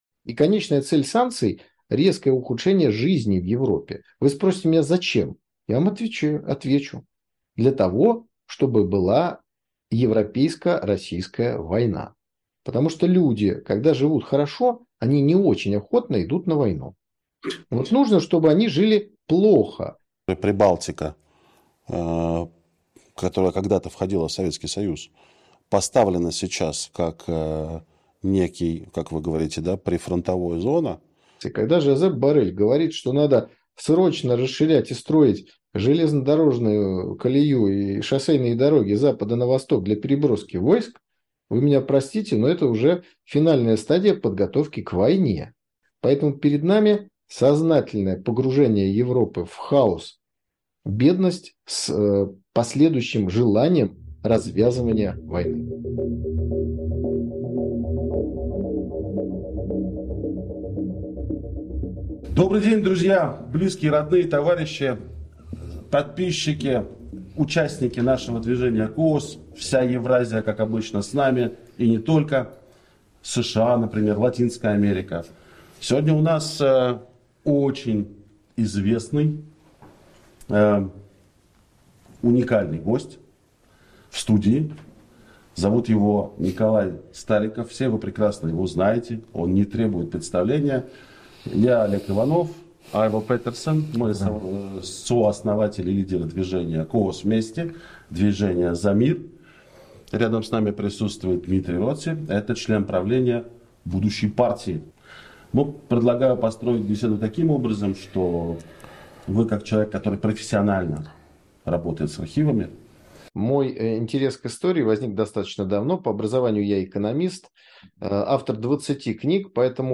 Поскольку общение у нас получилось длительным, беседу разделили на две части.